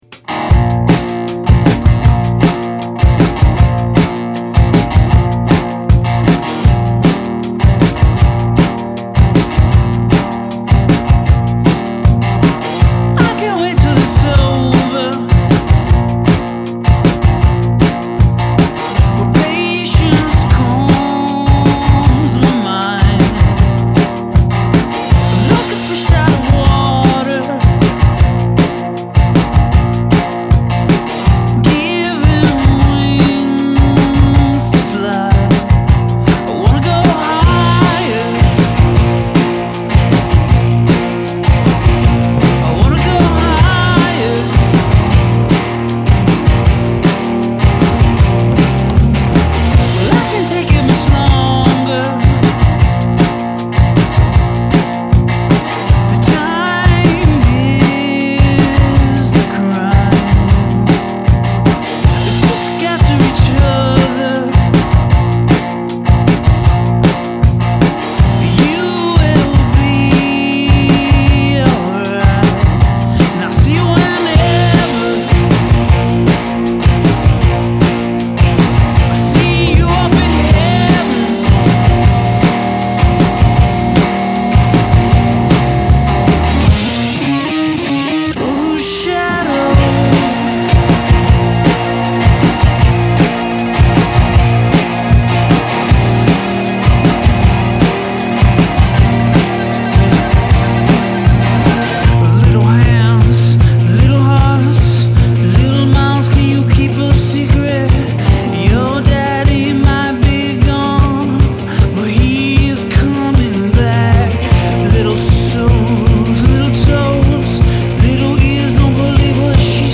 the sound of a band actually enjoying themselves